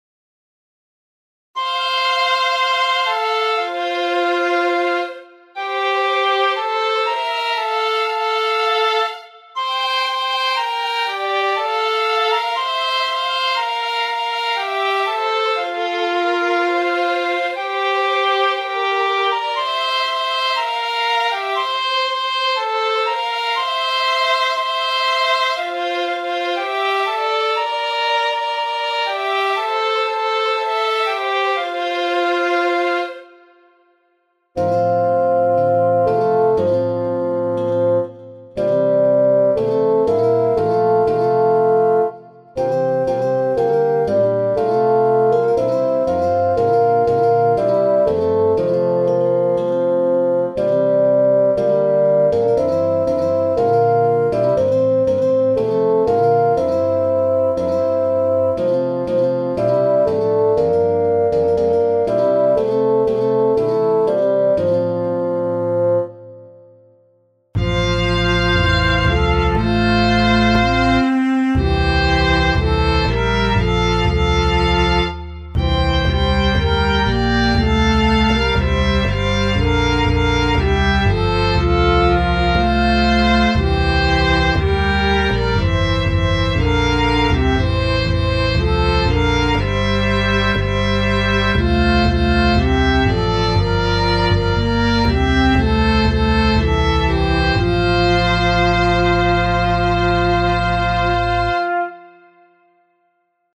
Corale 4 vozes F – mayor